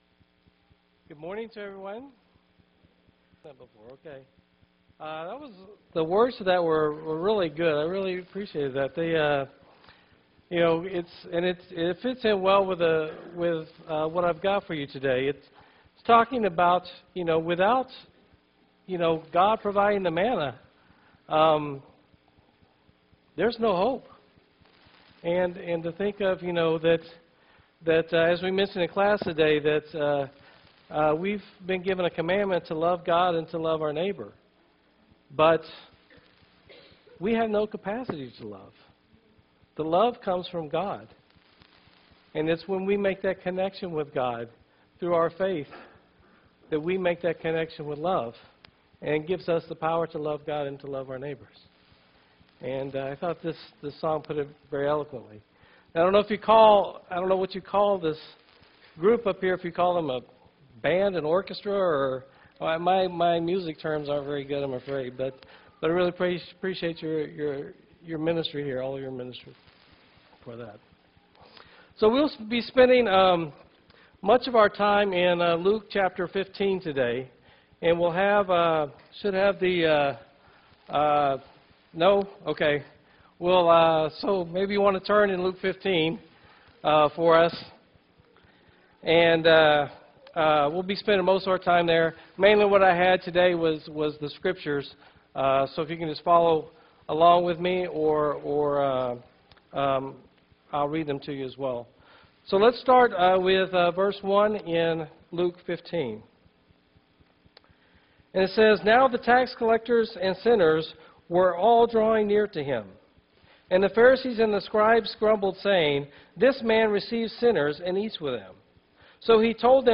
5-4-2013 sermon